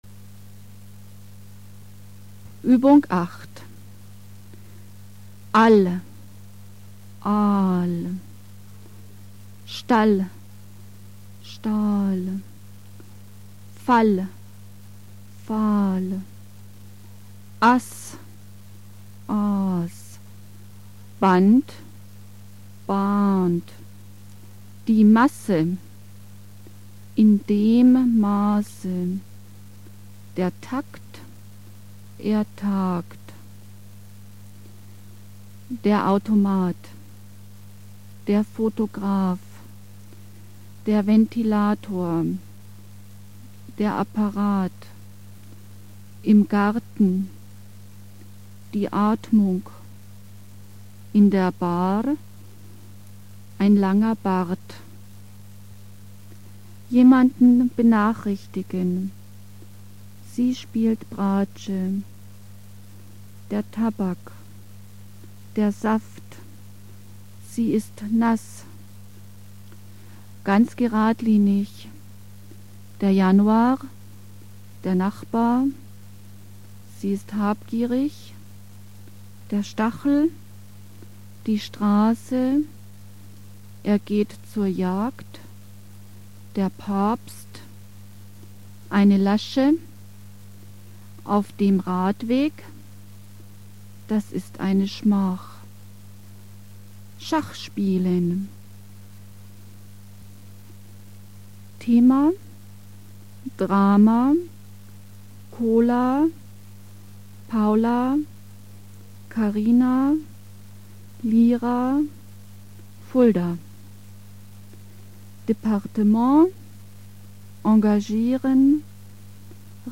Übung 8 (S. 44): Die a -Laute